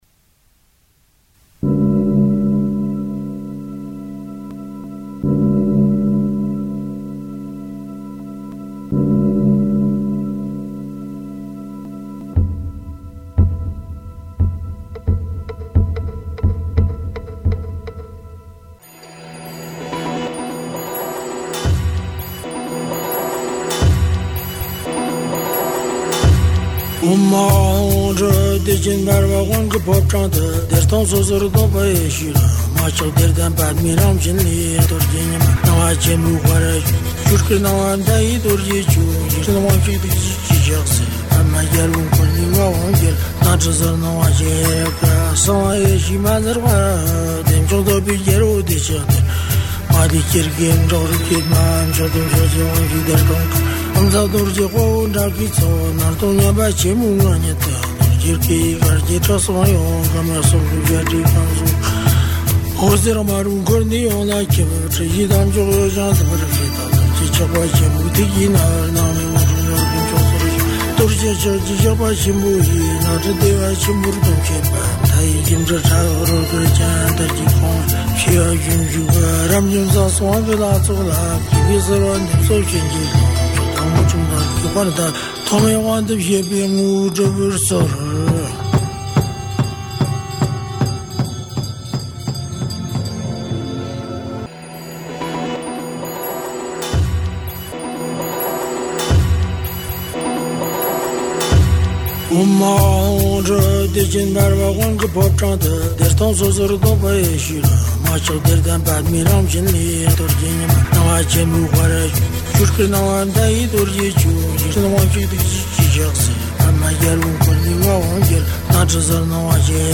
有一段配乐诵经，就是《大自在祈祷文》全文（三次），诵经者是法王如意宝晋美彭措仁波切。